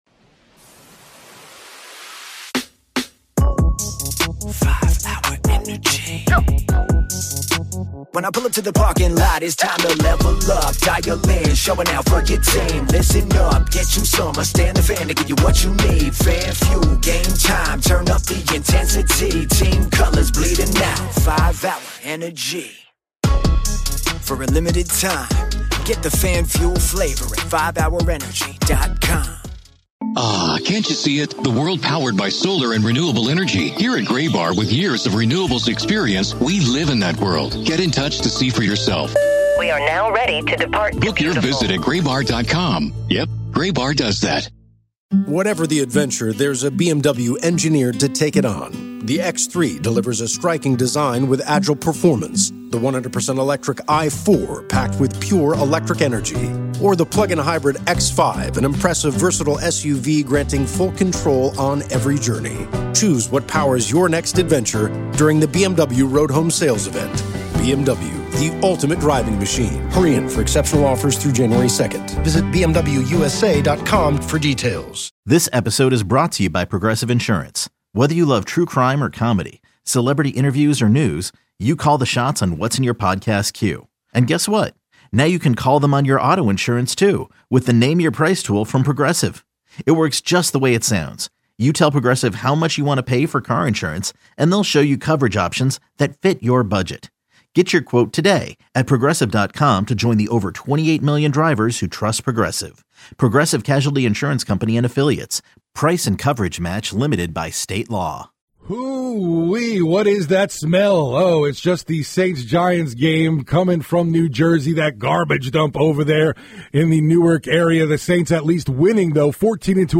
Saints interviews, press conferences and more